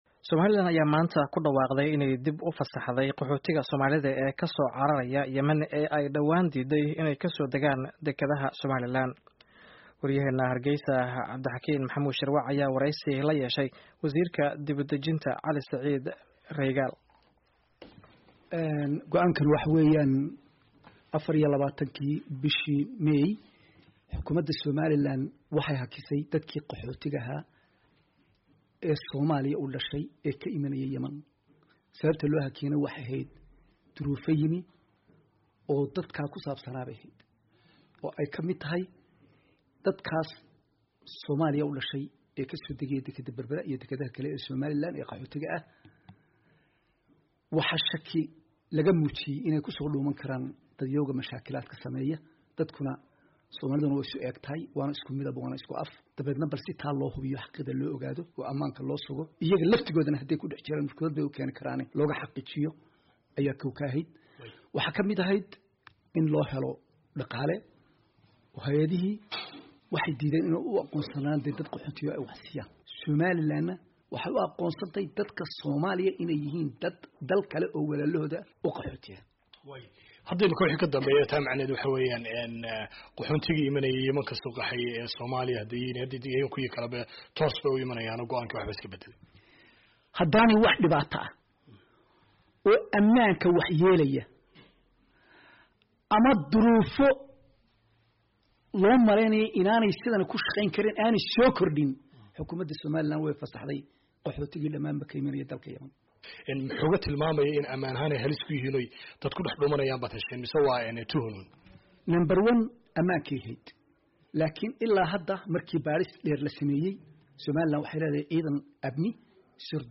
Waraysiga Wasiirka Dib u Dejinta